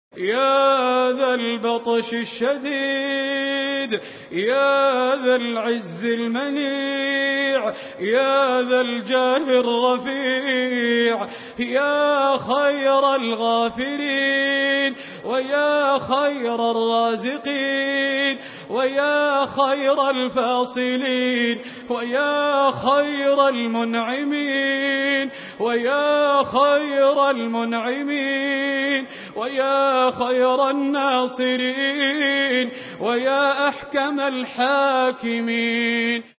الأنواع: دعاء